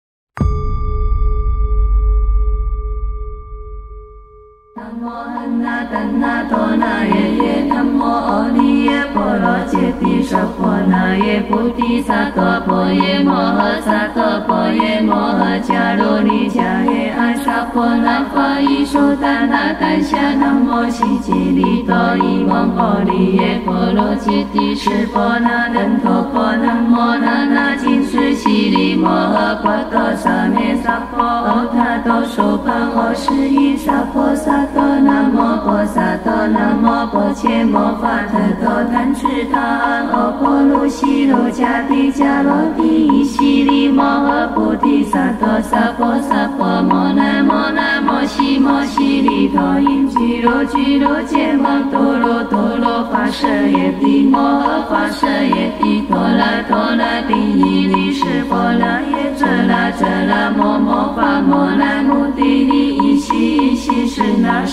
chant.wav